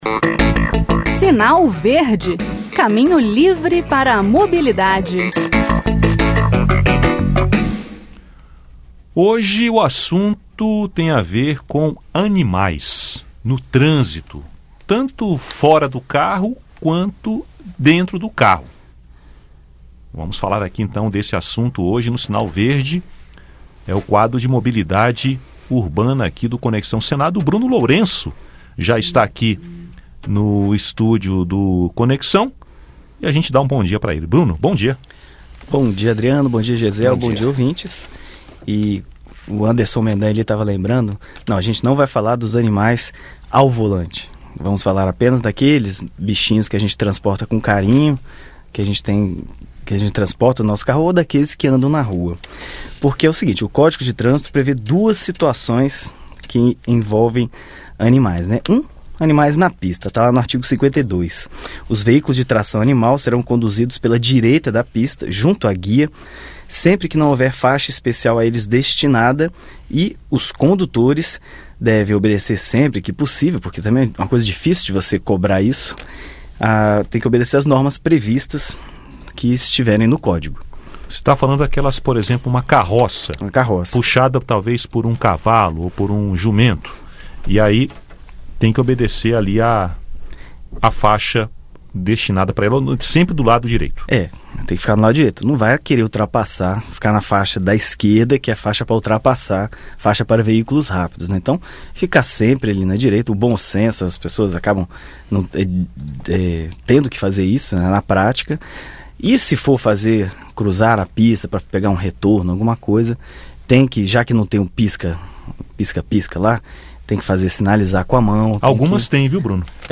No quadro Sinal Verde desta quarta-feira (15), o jornalista